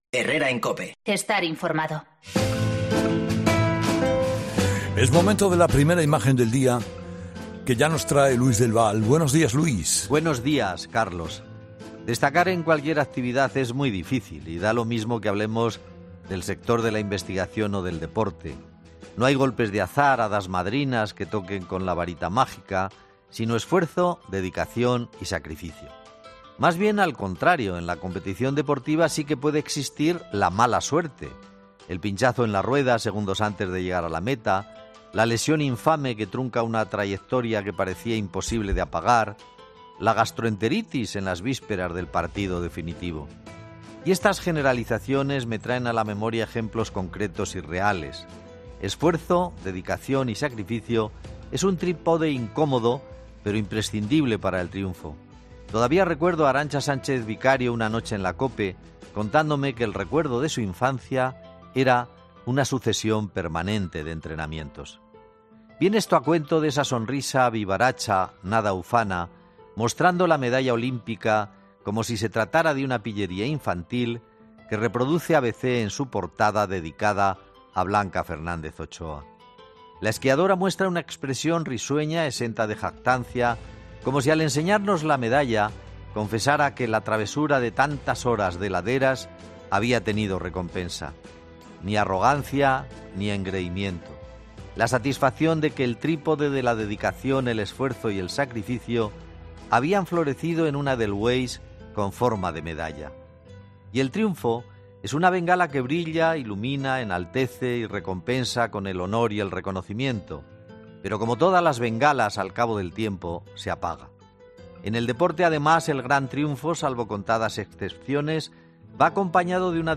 El colaborador de "Herrera en COPE" dedica unas bonitas palabras a la esquiadora